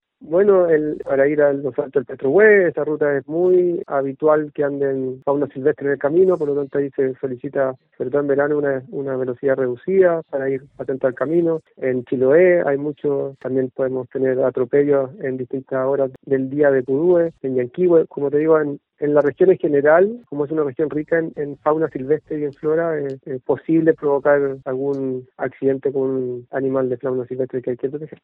En conversación con Radio Bío Bío en Puerto Montt, el director regional del SAG, Francisco Briones, informó que, tras conocer del caso por redes sociales, se activó el protocolo y personal del servicio evaluó en terreno al ejemplar y luego la trasladó al Centro de Rehabilitación de Fauna Silvestre de la Universidad San Sebastián.